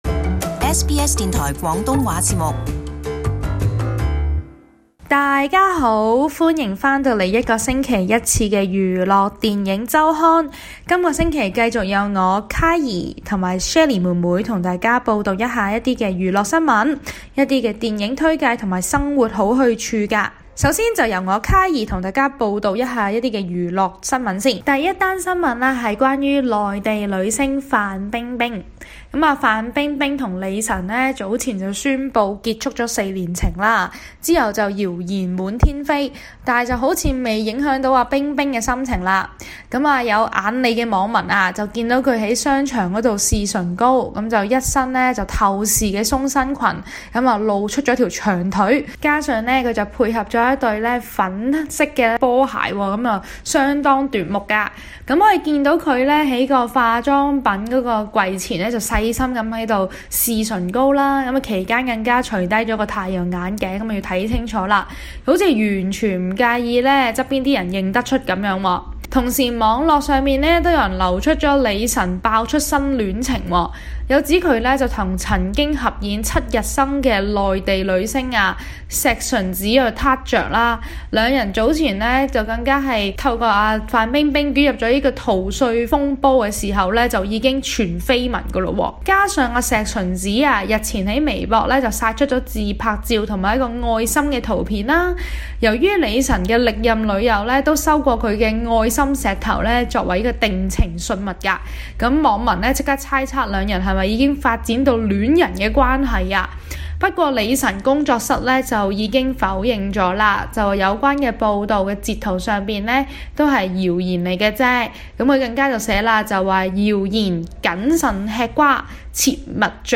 年青人主持